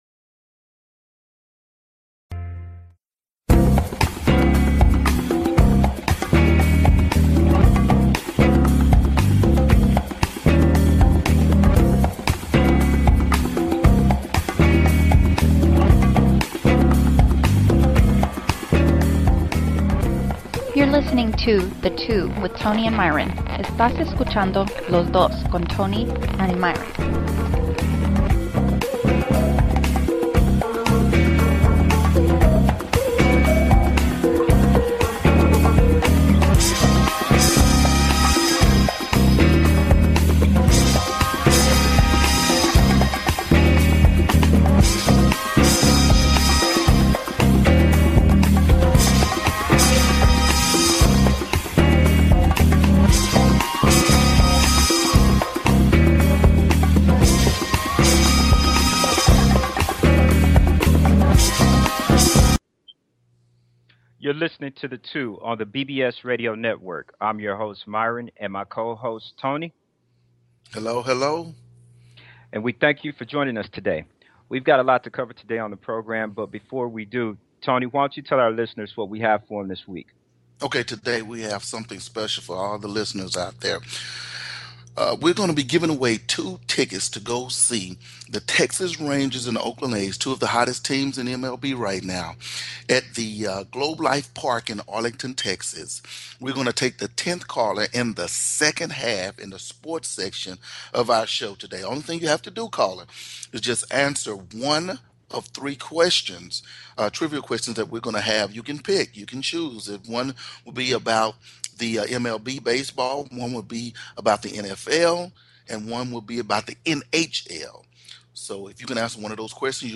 The program is a provocative variety talk show including social issues, politics and sports.